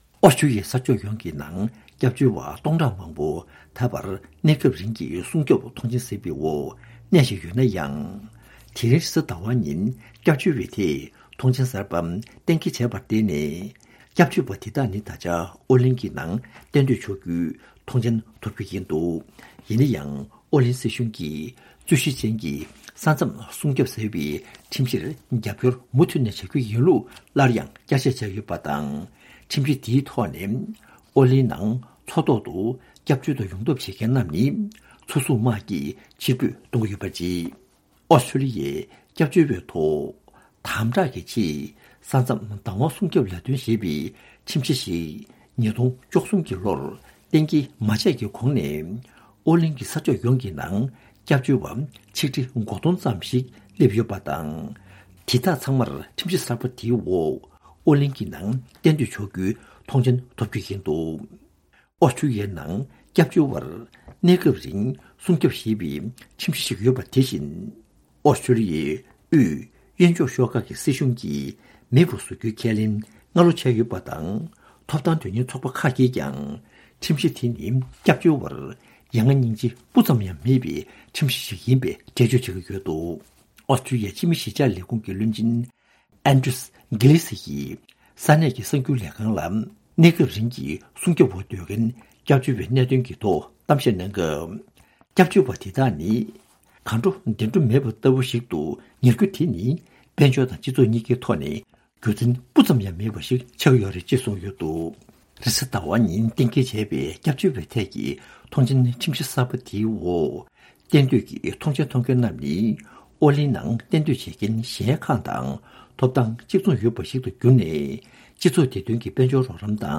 ཕྱོགས་བསྒྲིགས་གིས་སྙན་སྒྲོན་གནང་ཞིག་འདིར་གསལ།